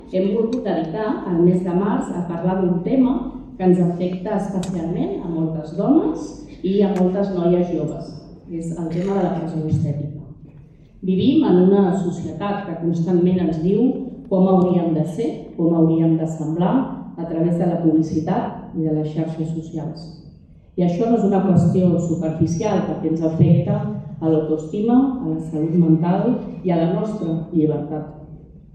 La Sala Albéniz ha acollit aquest dissabte 7 de març a les dotze del migdia l’acte institucional del 8M a Tiana, que enguany ha posat el focus en la pressió estètica i el cos. “Vivim en una societat que constantment ens diu com hauríem de ser, com hauríem de semblar, a través de la publicitat i de les xarxes socials. I això no és una qüestió superficial perquè ens afecta a l’autoestima, a la salut mental i a la nostra llibertat”, ha dit la regidora d’Igualtat, Susanna Capell: